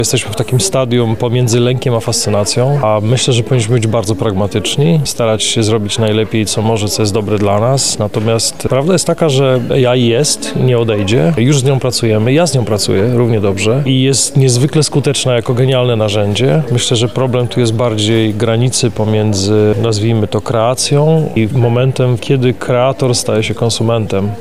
Dziś (19 marca) na Wydziale Politologii i Dziennikarstwa UMCS odbyło się kolejne spotkanie z cyklu „Open debates” Jego tematem była „Muzyka i sztuczna inteligencja – wyzwania dla twórców, rynku i administracji publicznej.
Leszek Biolik– mówi Leszek Biolik, kompozytor, basista i producent muzyczny, członek zespołu Republika.